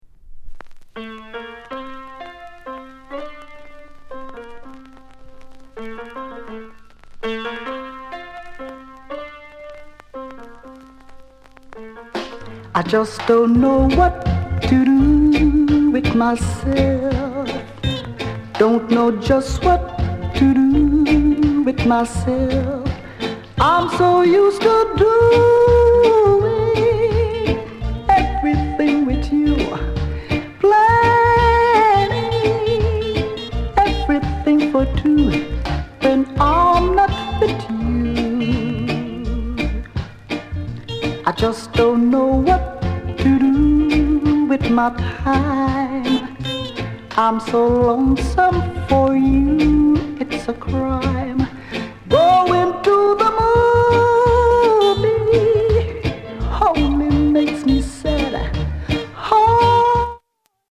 very slightly warp ������ NICE VOCAL